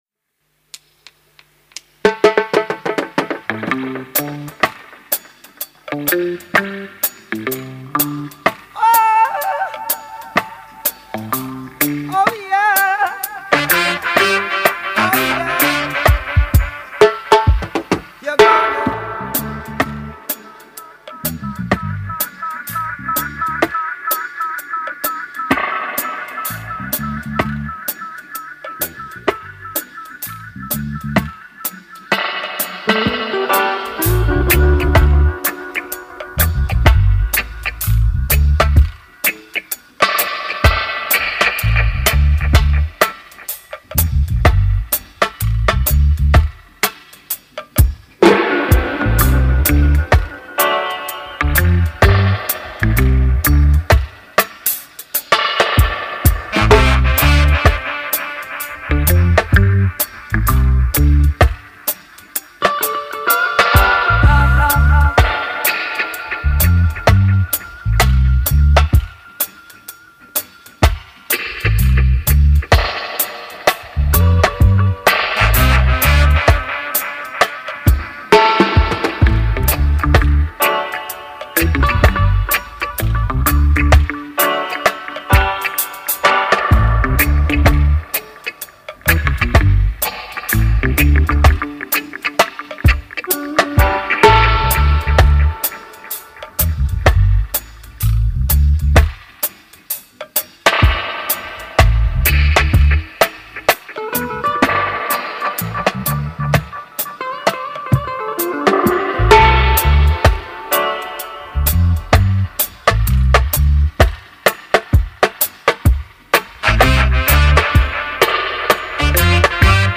My dub takes on the two classic tunes